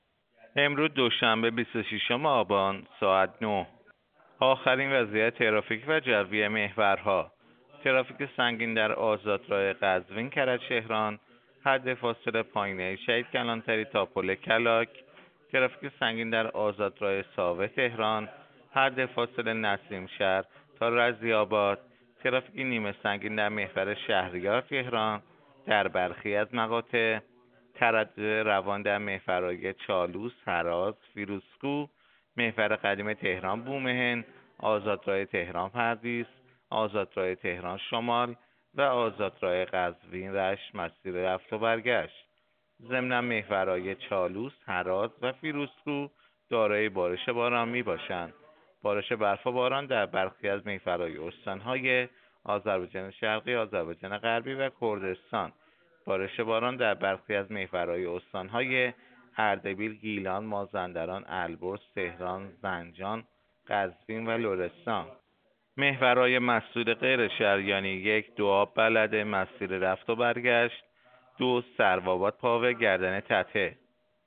گزارش رادیو اینترنتی از آخرین وضعیت ترافیکی جاده‌ها ساعت ۹ بیست و ششم آبان؛